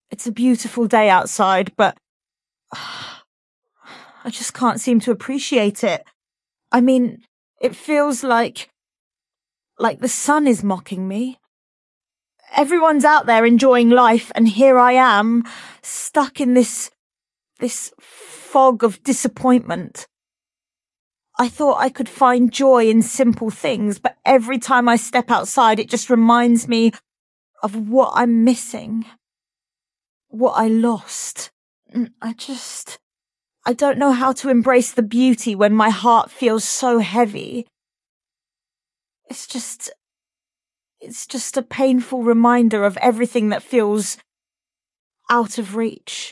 I told CoPilot that it was a beautiful day outside, chose the sadness mode.